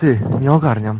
Worms speechbanks
stupid.wav